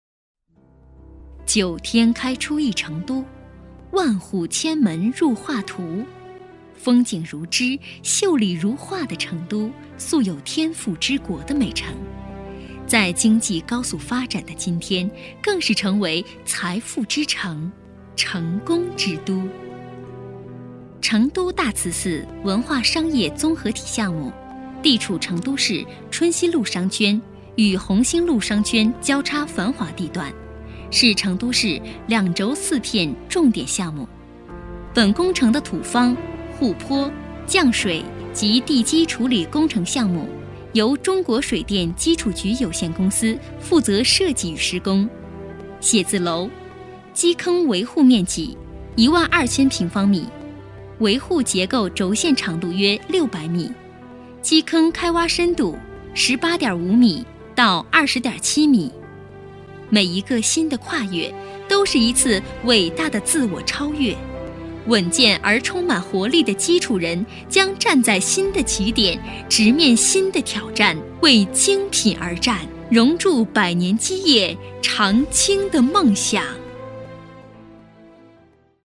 Voz femenina china
La siguiente locución en chino es un proyecto de construcción en la ciudad de Chengdu narrada por voz femenina china.
Locutor-femenino-chino-4.mp3